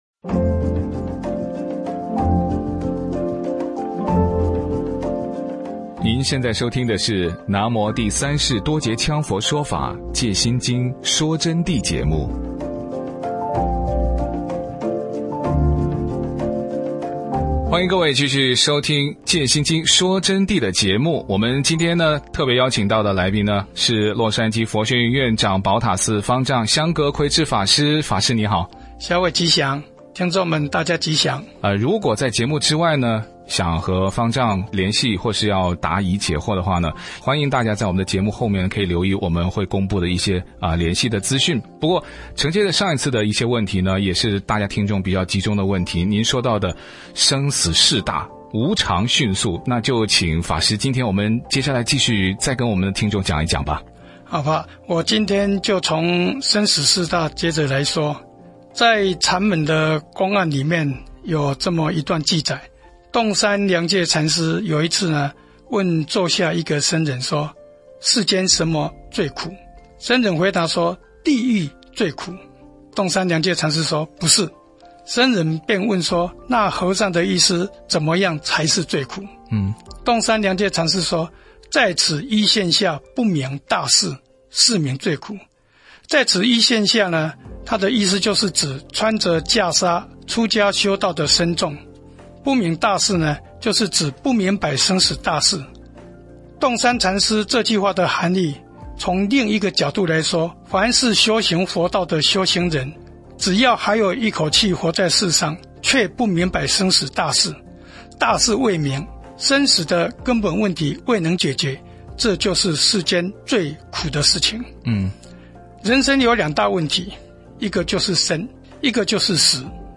从 2021 年 8 月 1 日开始，KAZN AM1300 中文广播电台每周日早上九点播出
第二阶段专访节目